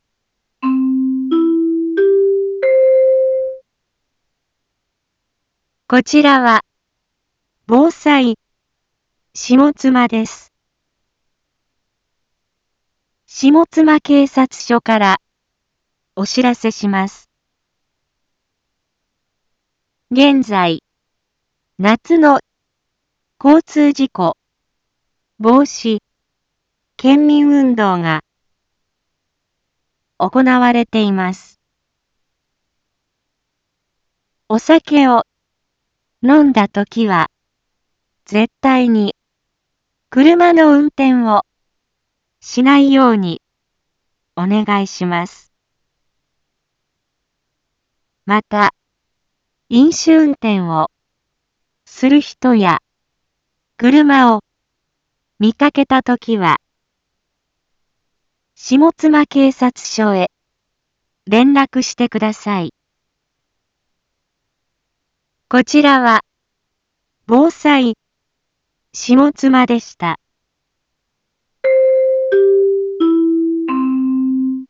一般放送情報
Back Home 一般放送情報 音声放送 再生 一般放送情報 登録日時：2021-07-23 17:31:18 タイトル：夏の交通事故防止県民運動 インフォメーション：こちらは、防災下妻です。